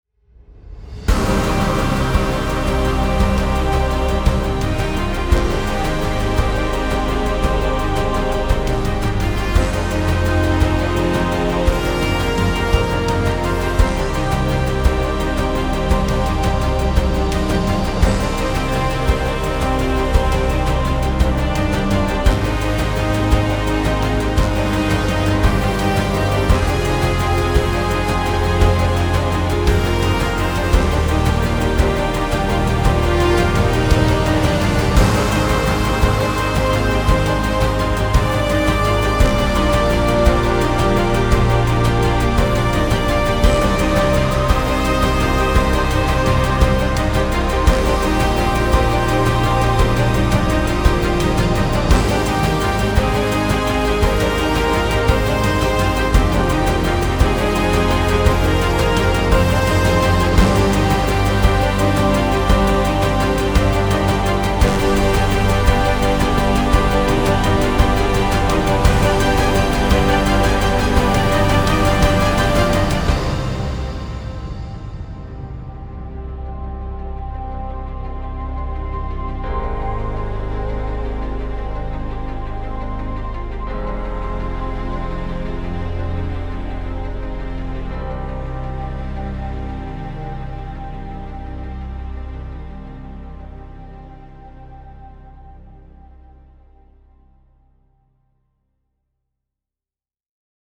big Version